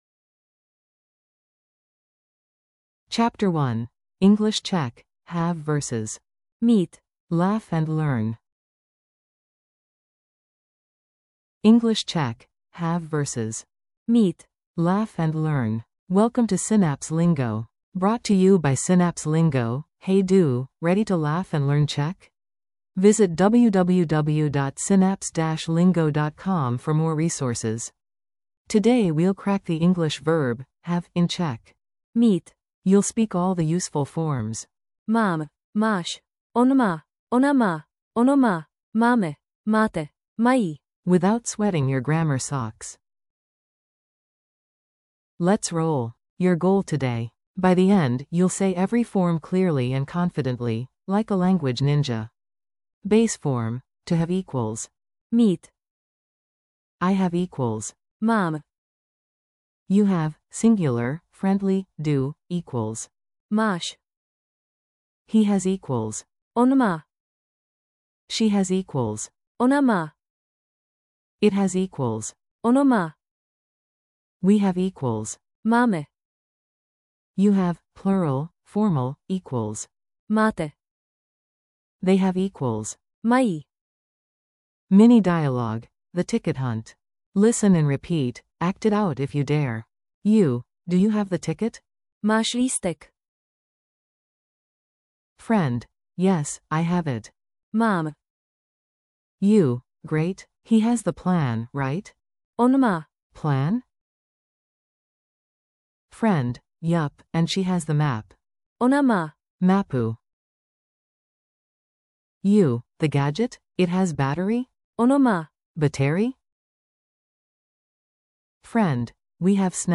Audio for repeating & practicing